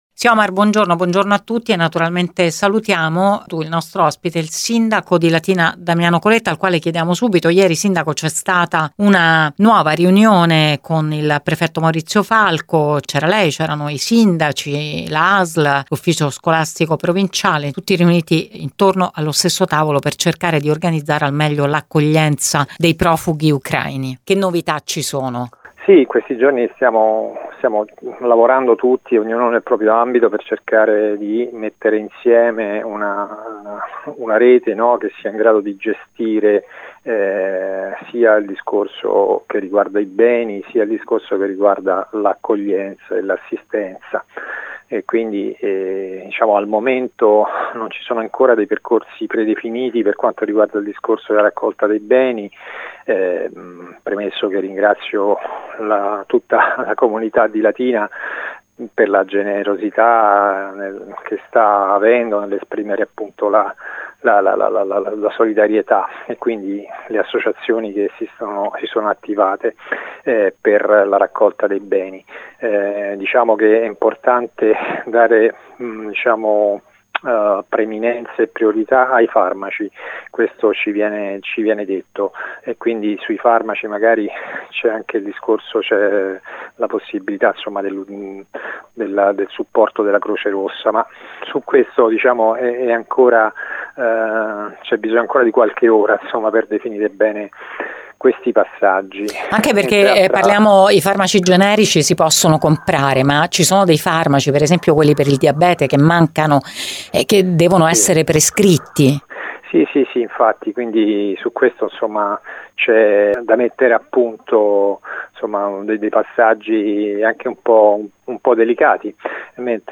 Il punto con primo cittadino di Latina Damiano Coletta nel nostro approfondimento su Radio Immagine.